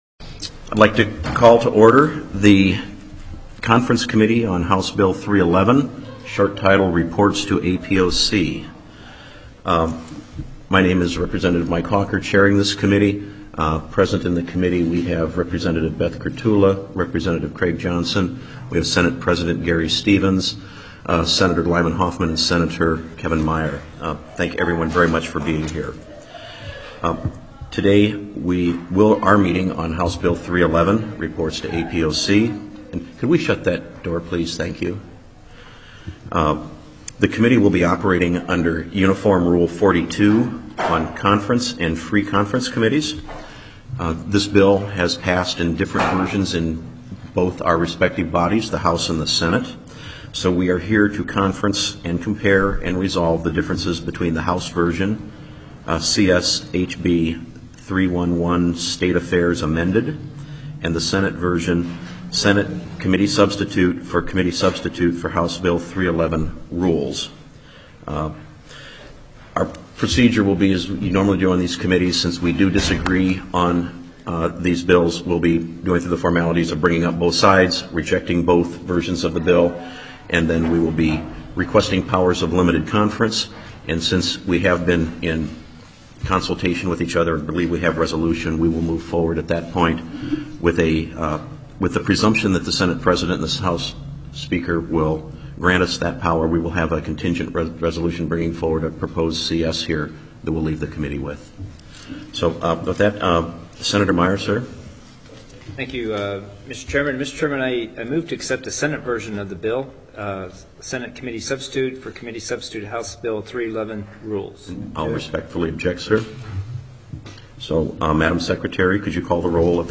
02/15/2012 11:30 AM Senate CONFERENCE COMMITTEE ON HB 311
Teleconference Listen Only